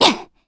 Valance-Vox_Attack4_kr.wav